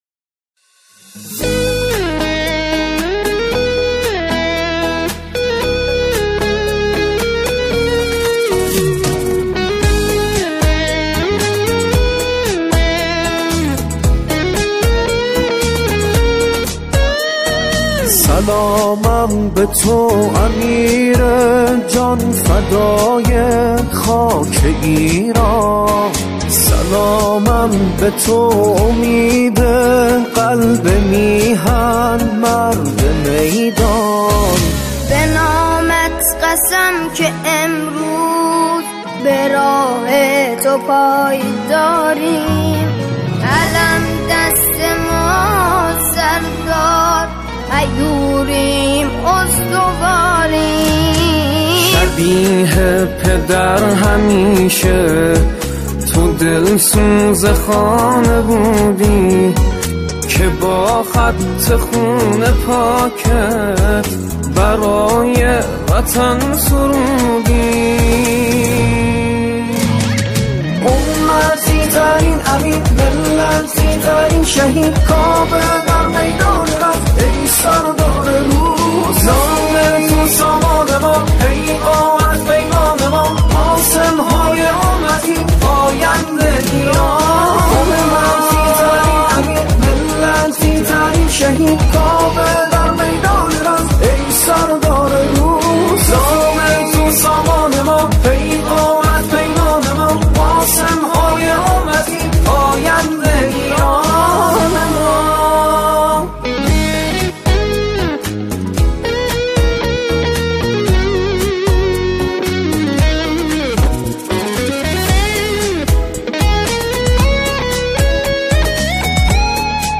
قطعه
همخوانی